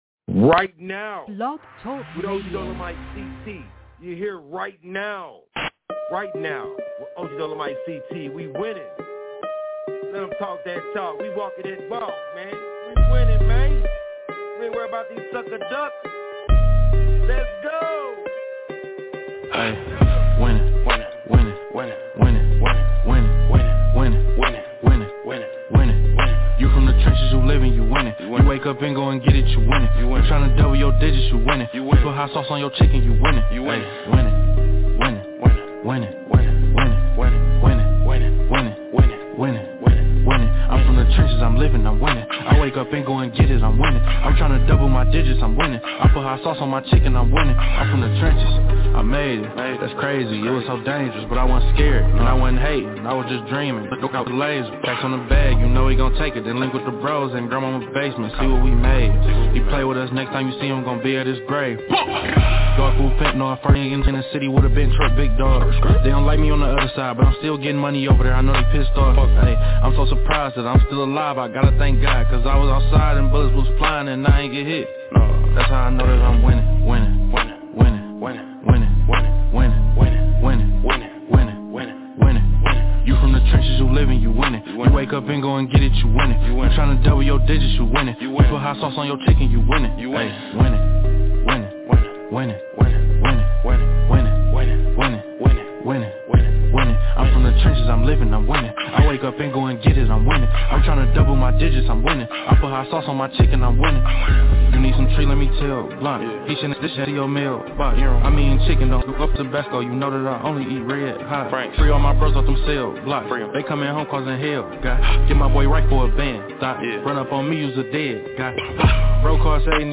We're back again with nonstop hits